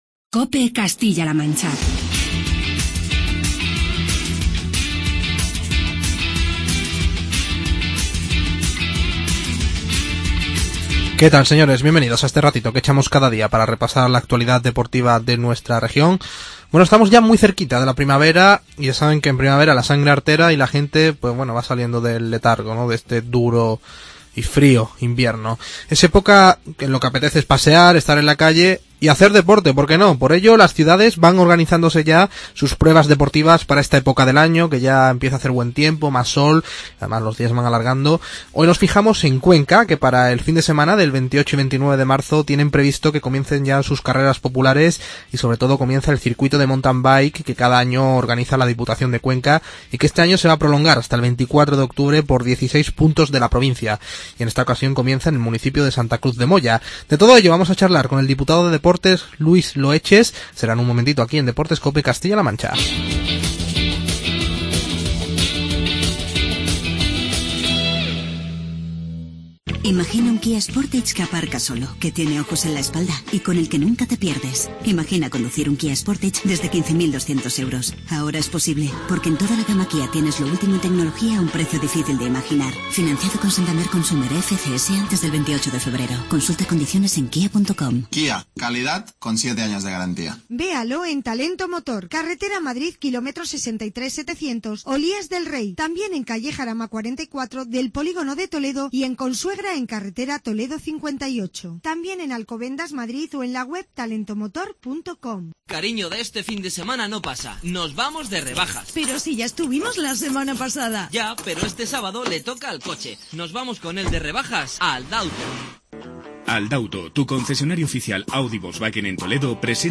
Charlamos con Luis Loeches, diputado de Deportes de la Diputación de Cuenca